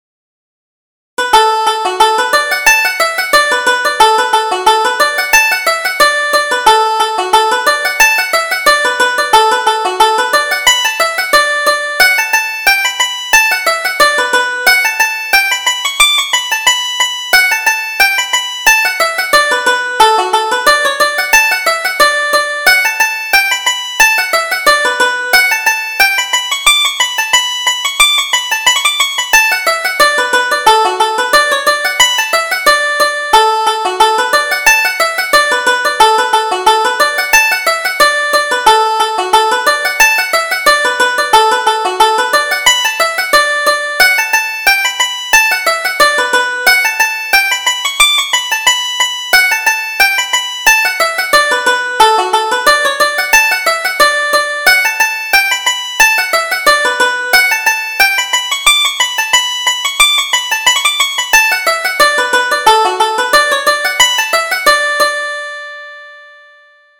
Reel: You're Right My Love